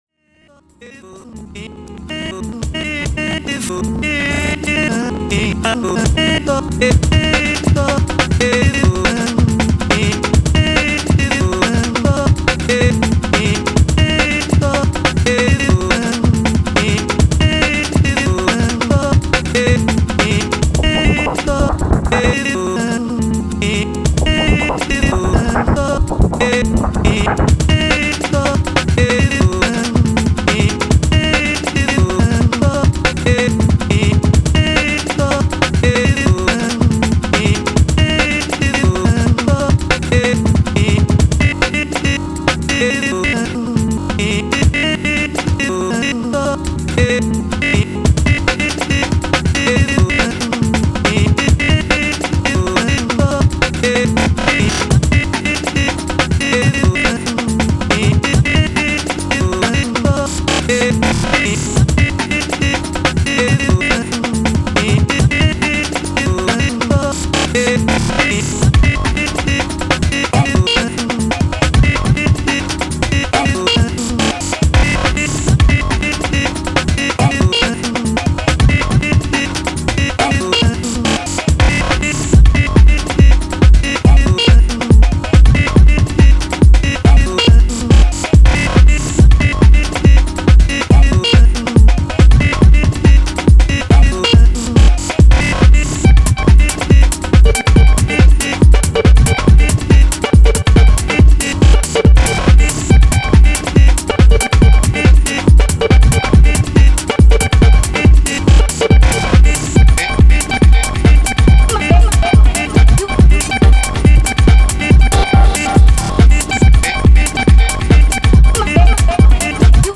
Digitakt, 3 tracks: